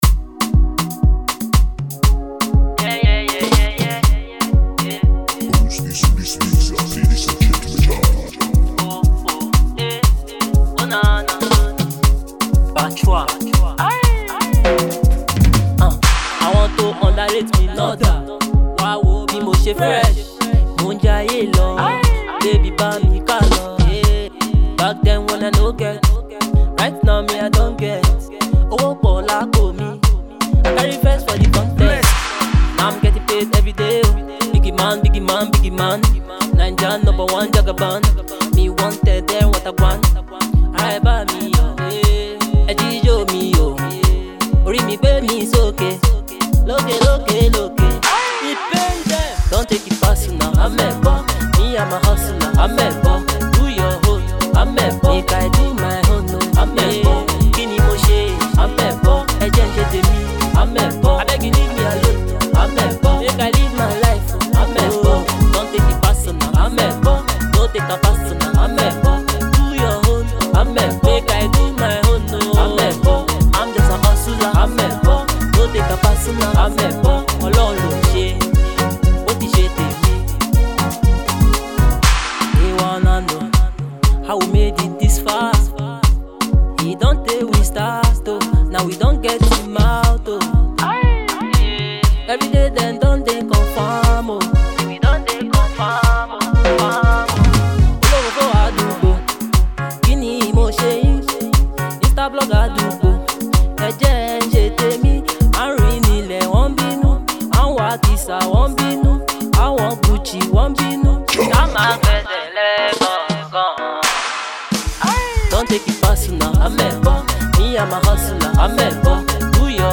a passionate Nigerian singer, songwriter